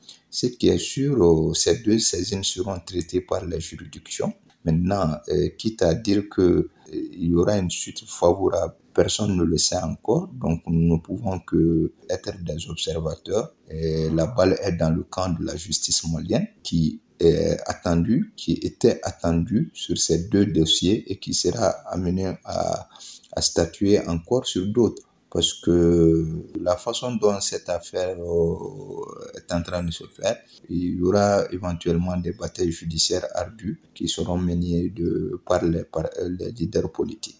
02-REACTION-JURISTE-FR.mp3